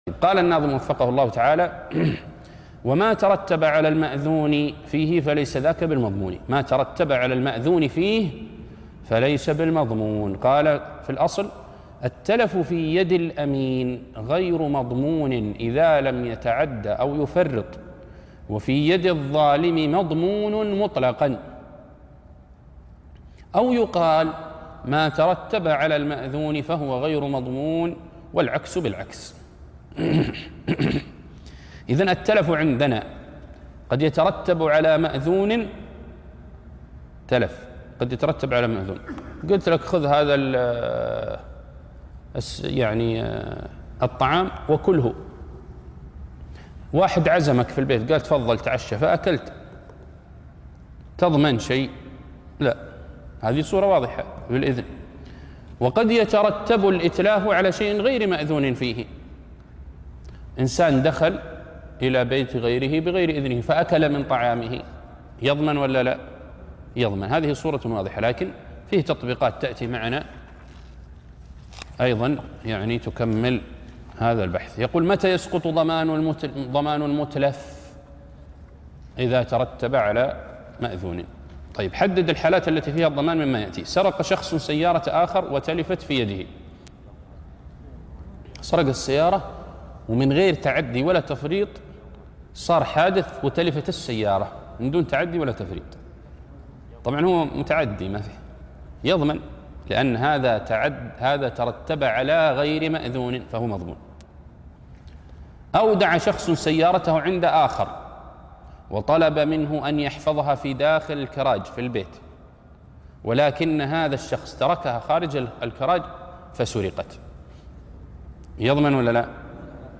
عنوان المادة الدرس ( 14) ( المساعد لفهم نظم القواعد)